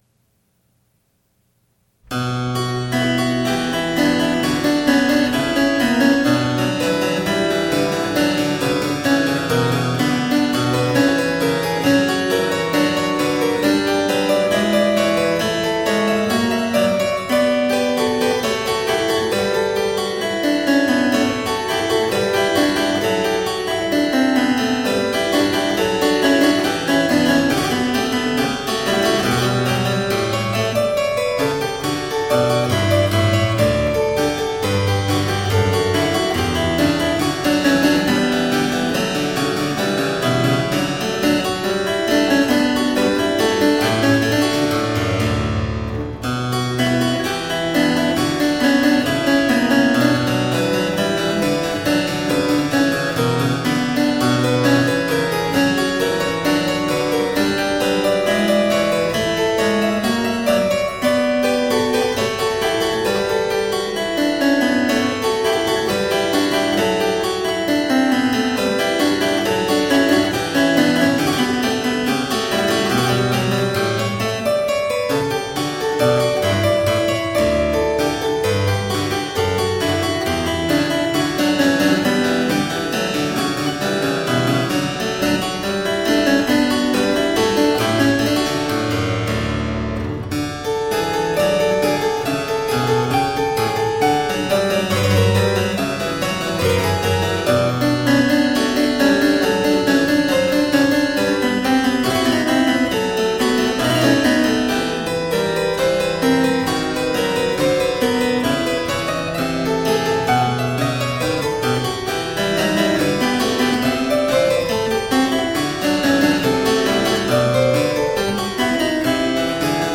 Lilting renaissance & baroque vocal interpretations .